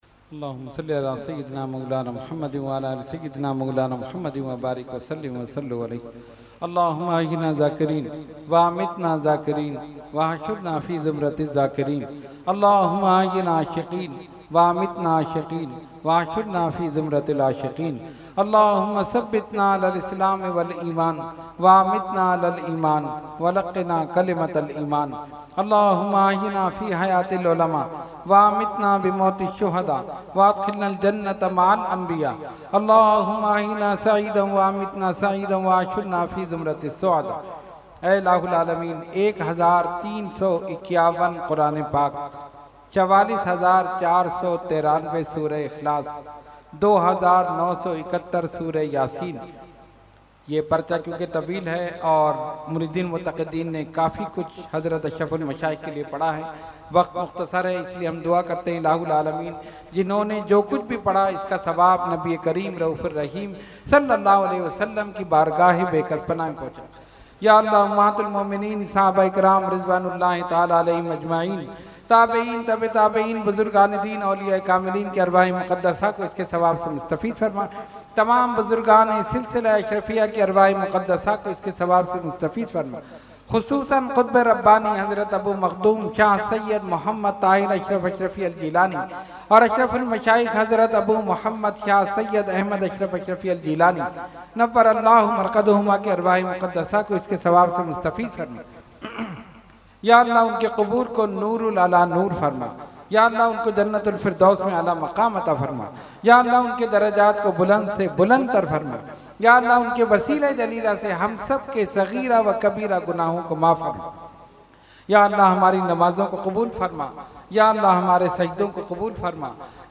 Category : Dua | Language : UrduEvent : Urs Ashraful Mashaikh 2019